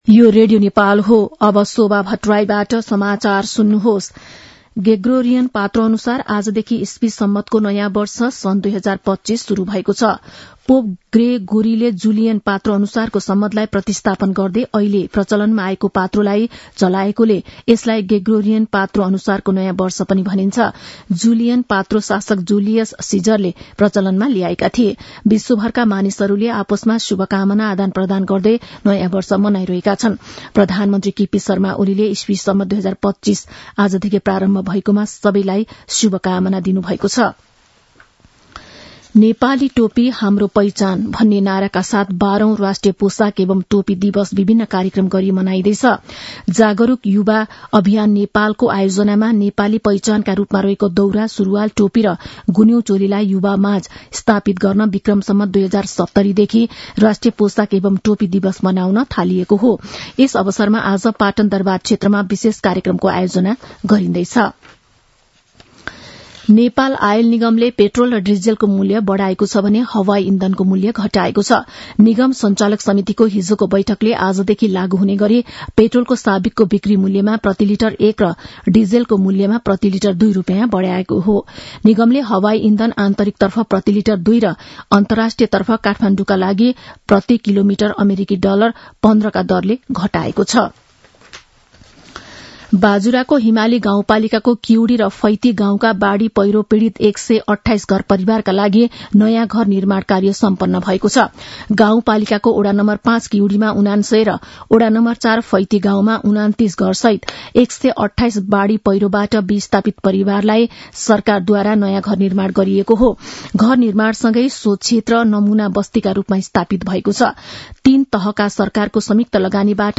मध्यान्ह १२ बजेको नेपाली समाचार : १८ पुष , २०८१
12-am-nepali-news-.mp3